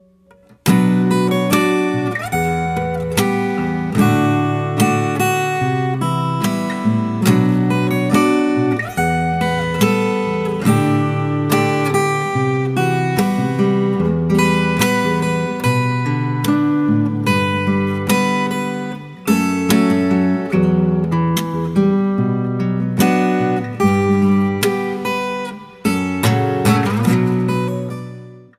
• Качество: 320, Stereo
гитара
без слов
красивая мелодия
акустика
Мелодичная акустика